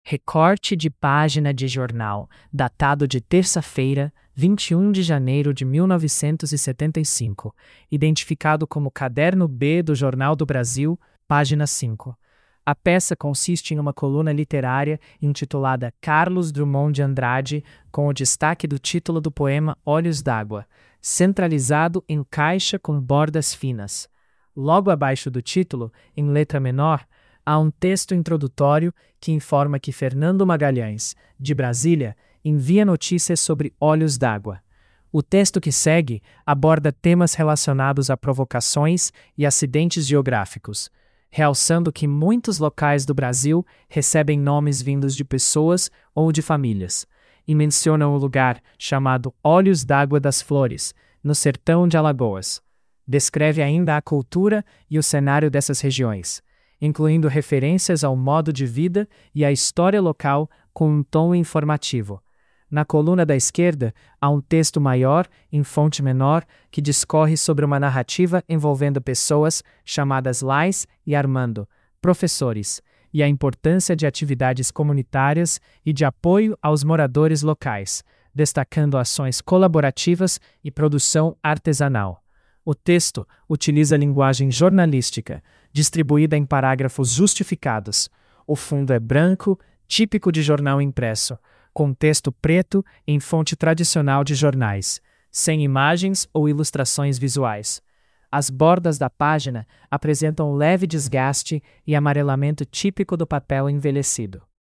QR code para acessar a audiodescrição da mídia Crônica de Drummond sobre Olhos D'Água (recorte)